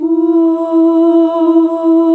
UUUUH   E.wav